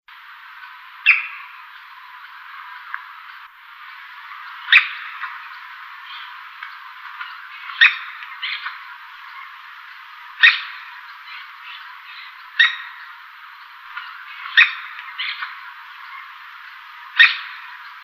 English Name: Green-barred Woodpecker
Location or protected area: Reserva Natural de Uso Múltiple Isla Martín García
Condition: Wild
Certainty: Recorded vocal